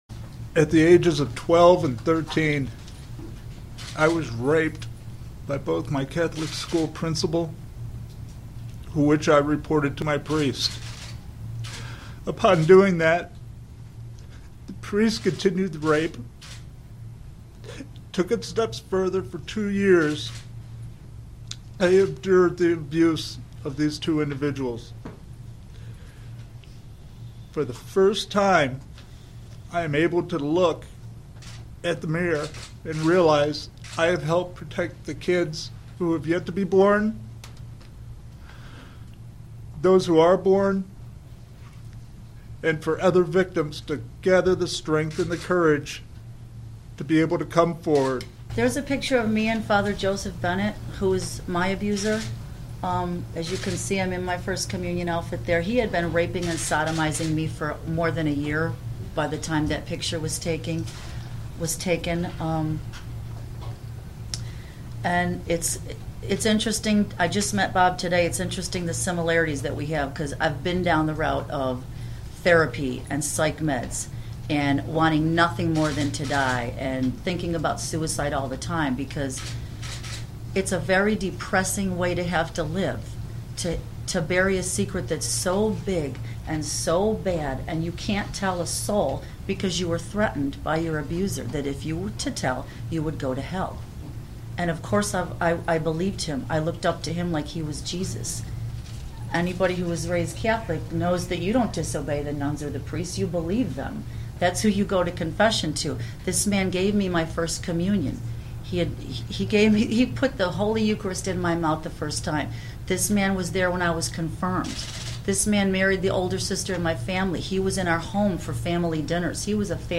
Priest Sex Abuse Victims Speak